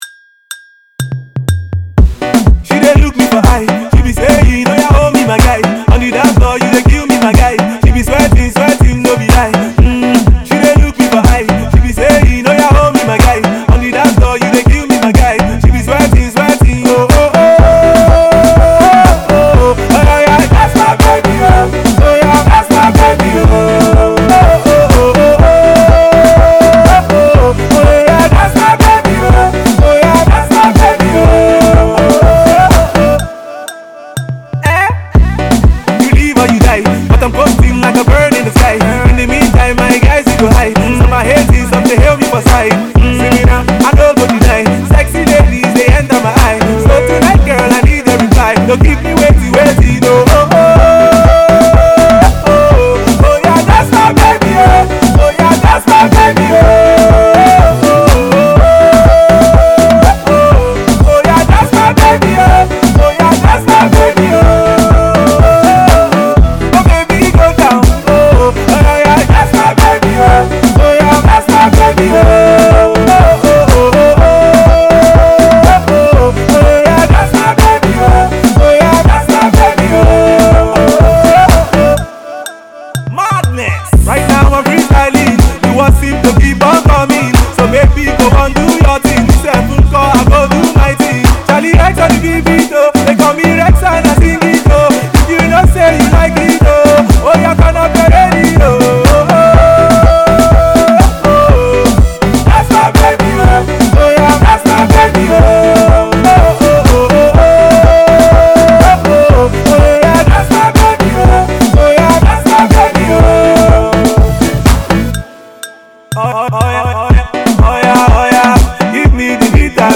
Azonto-inspired